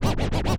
scratch02.wav